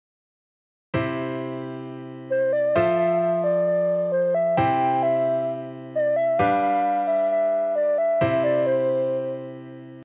（コードは、最初にCが鳴ってからF⇒C⇒G⇒Cです）
前のサンプルを一拍分前にズラしただけですが、印象が変わりましたね。